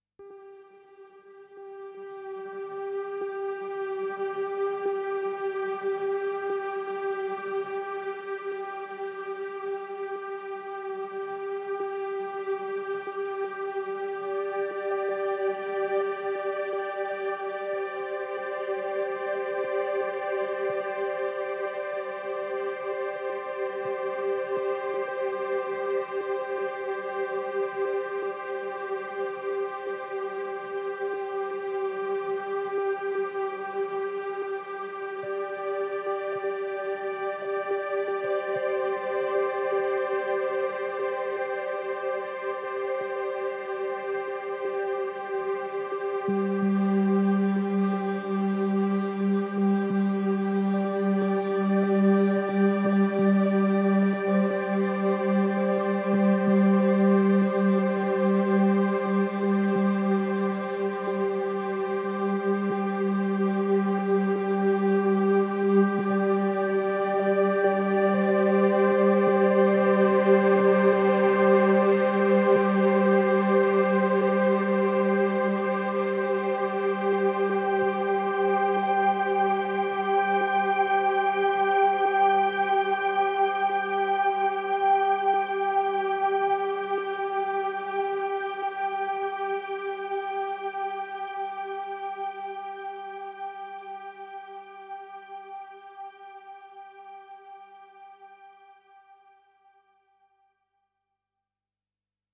Intriguingly dark and impulsively electronic.